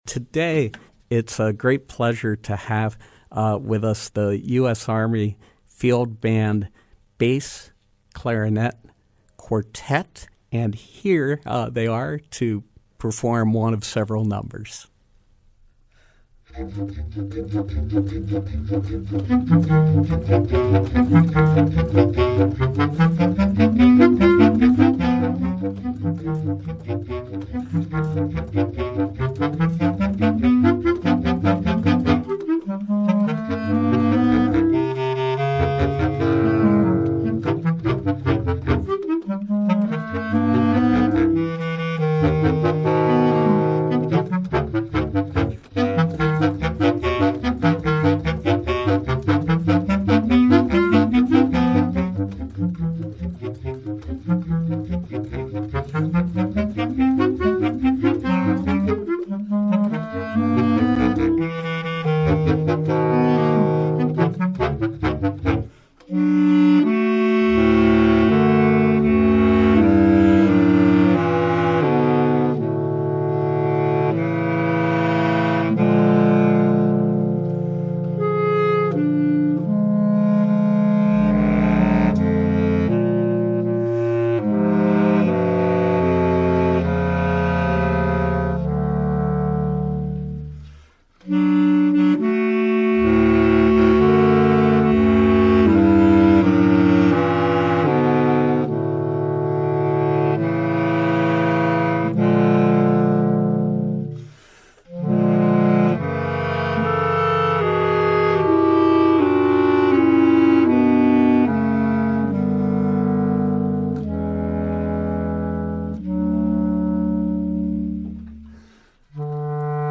In Studio Pop-up: U.S. Army Field Band Bass Clarinet Quartet
Bass clarinetists